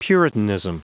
Prononciation du mot puritanism en anglais (fichier audio)
Prononciation du mot : puritanism